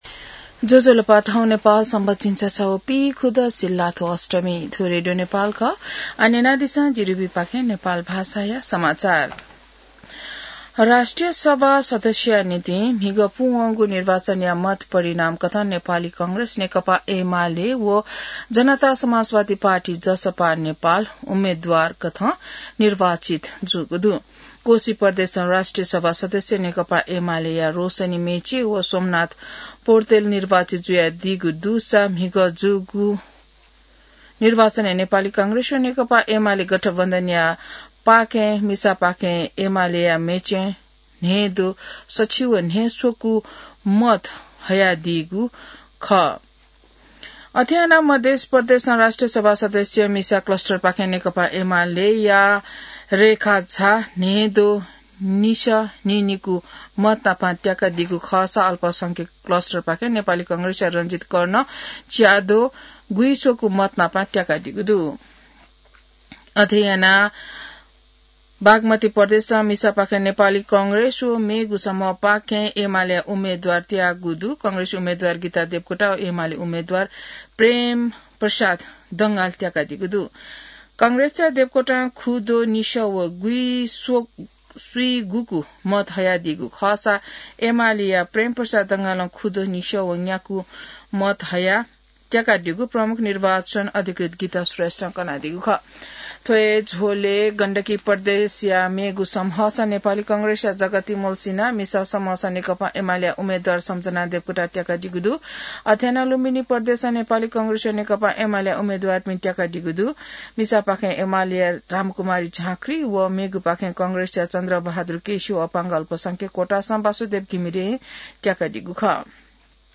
नेपाल भाषामा समाचार : १२ माघ , २०८२